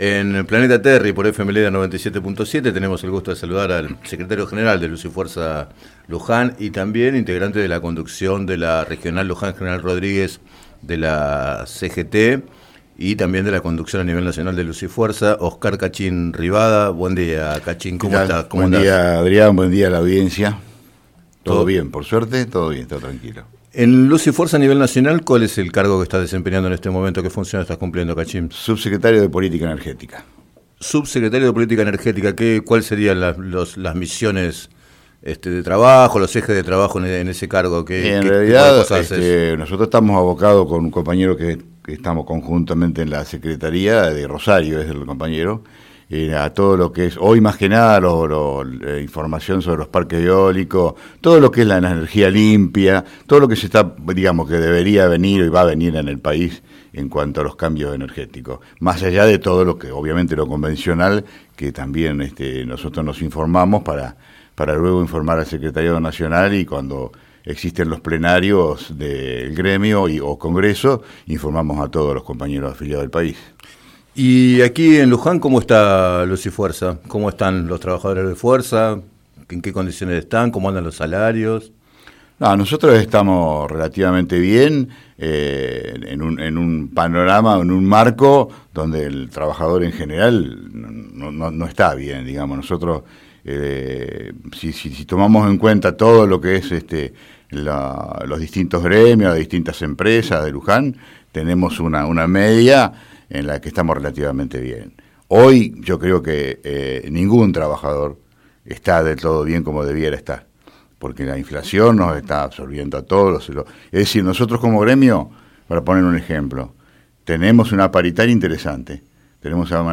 Entrevistado en el programa Planeta Terri de FM Líder 97.7